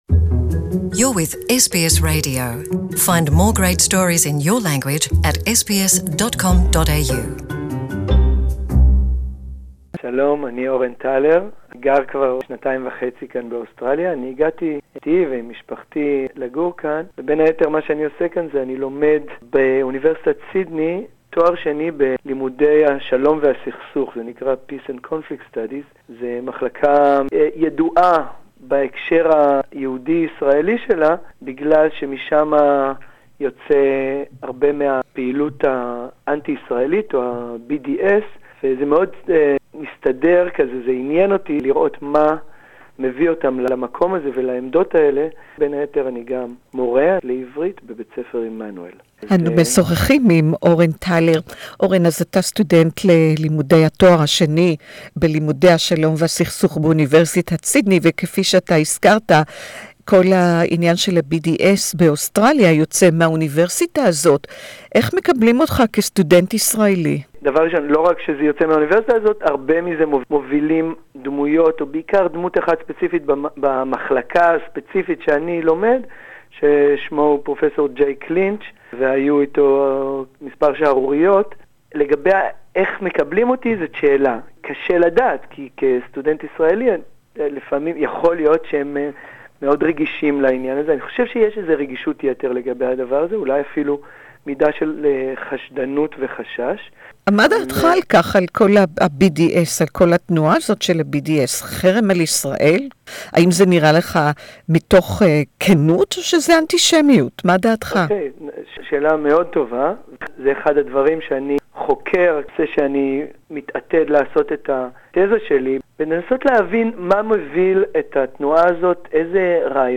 (Interview in Hebrew)